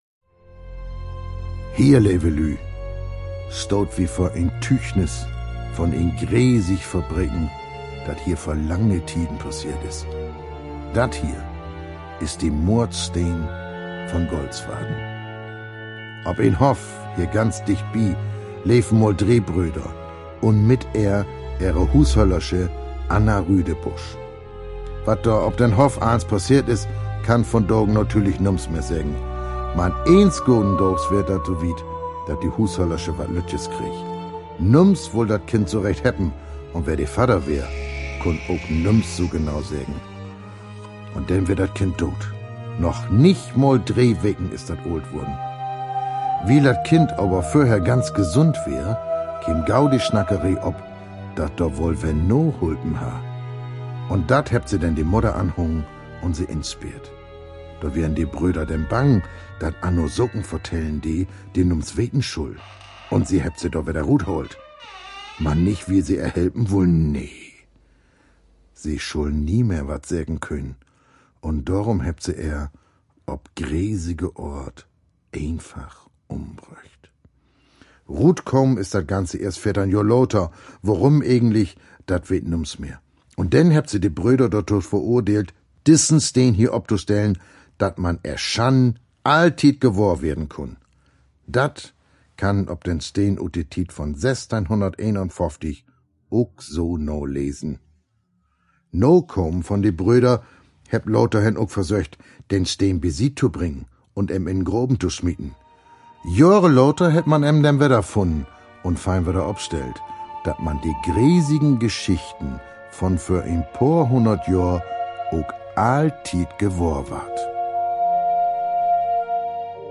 Der Mordstein in Golzwarden hört sich auf plattdeutsch zum Beispiel so an: